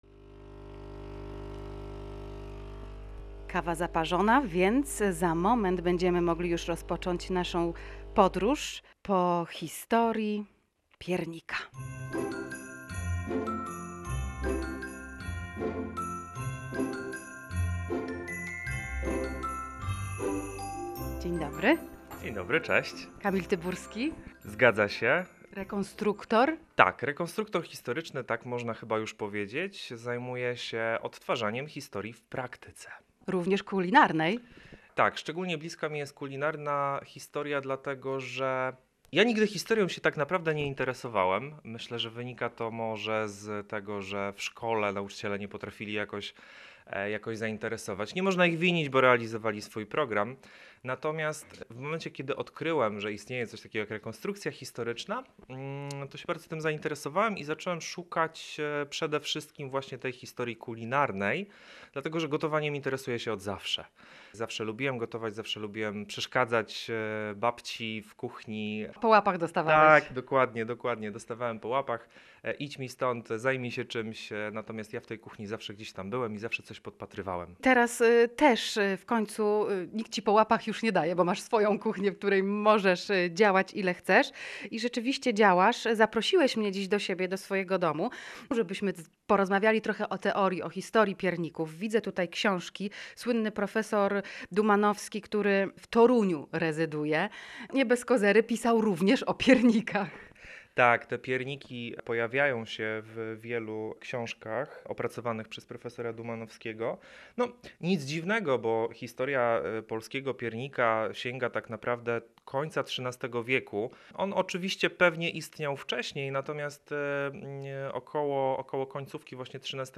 W audycji mówiliśmy również o formach piernikarskich i cechach piernikarzy. Jak się okazje, piernik wcale nie pochodzi historycznie z Torunia.